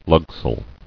[lug·sail]